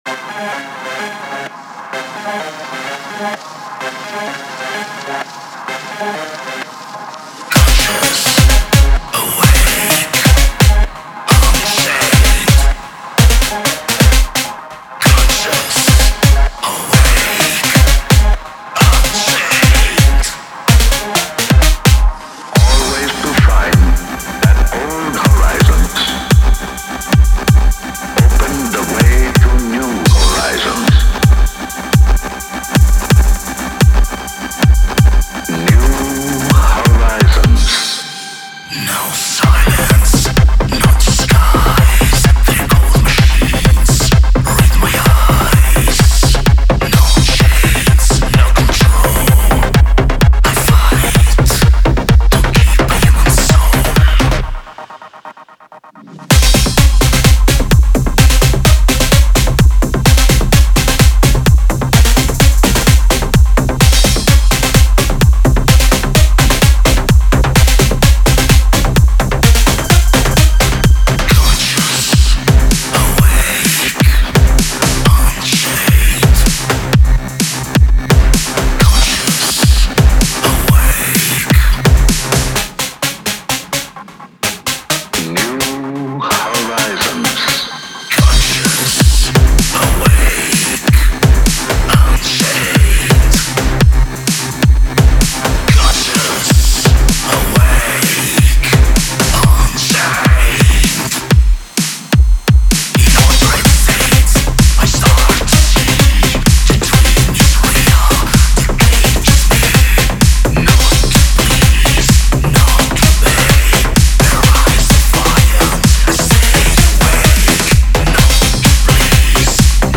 EBM, Industrial, Dark Electro, Aggrotech, Cyberindustrial
Genre: Dark Electro / Industrial / EBM
Moods: Aggressive, Cyber, Dystopian, Hypnotic